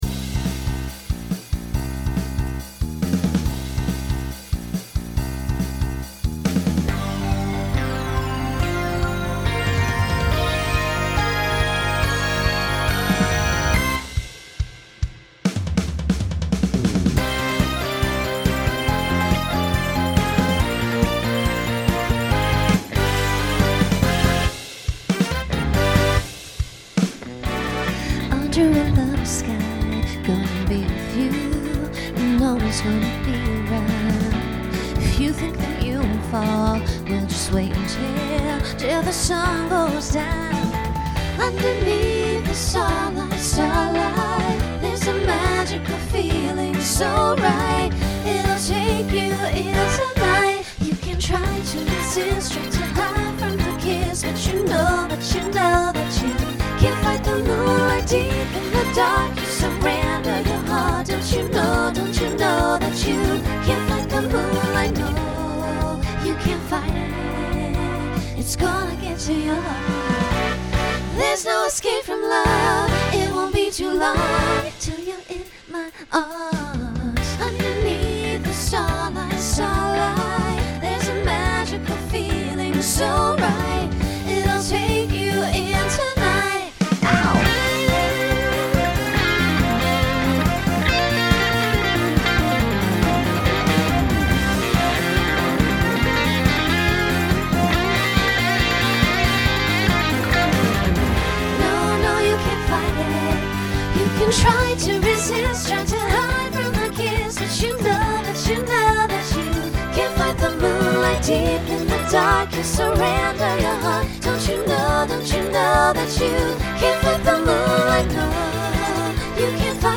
New SATB voicing for 2022.
Genre Pop/Dance
Transition Voicing SATB , SSA